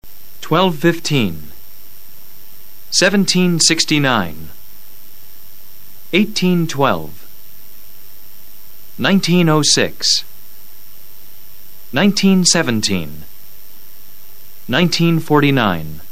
Practica la lectura de los AÑOS CALENDARIO. Repítelo luego con el profesor.